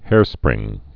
(hârsprĭng)